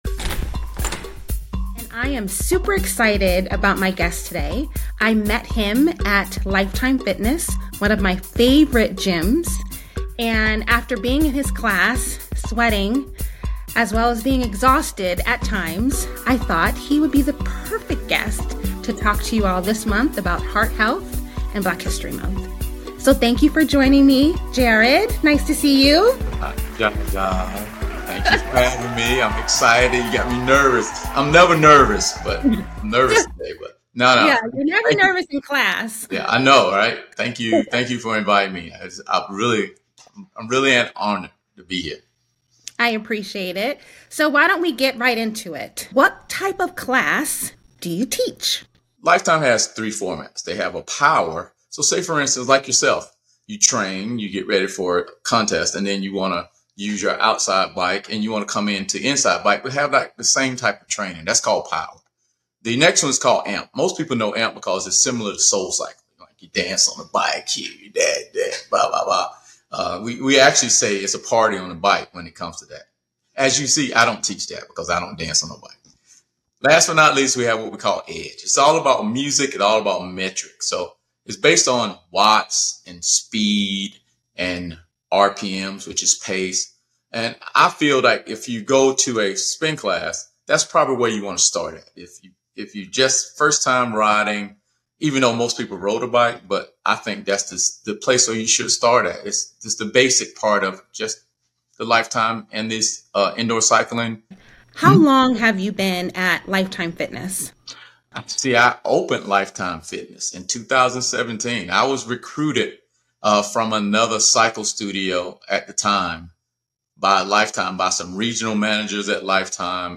An inspiring interview